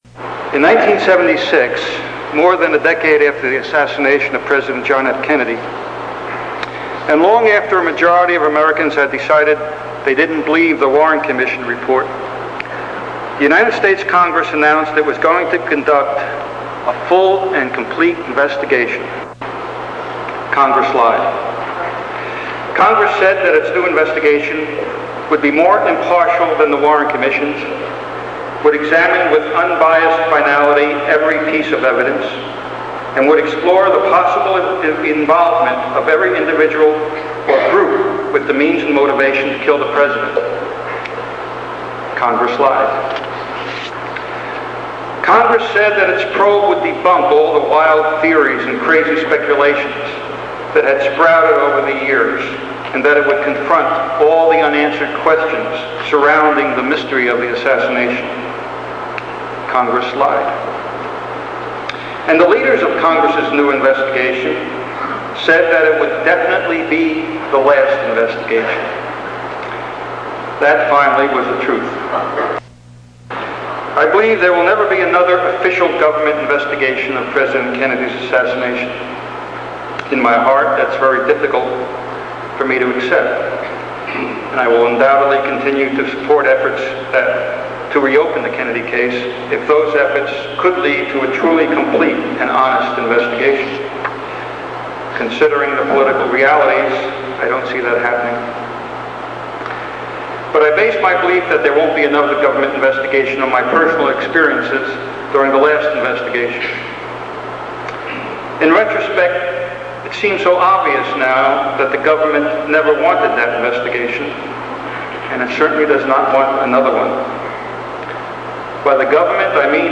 3rdDecadeKeynote.mp3